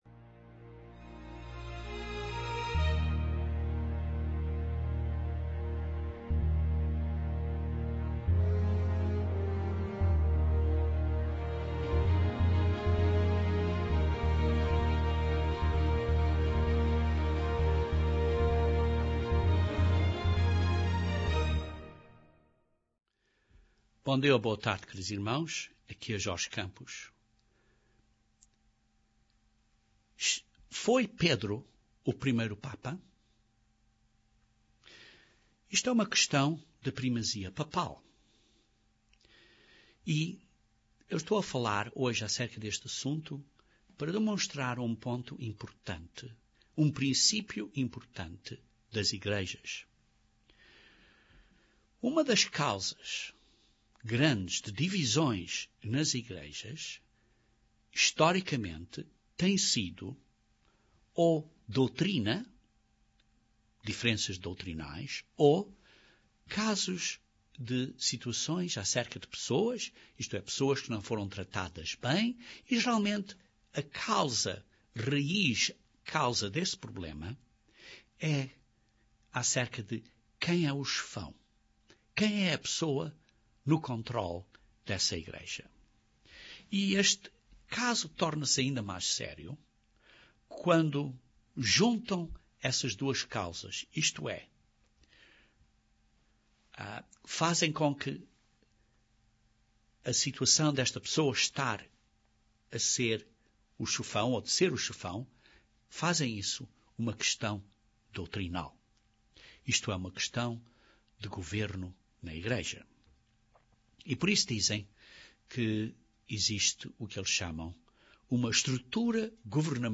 Usando o exemplo da questão da primazia Papal, este sermão analiza, bíblicamente, se Pedro esteve em Roma ou não.